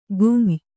♪ gum`y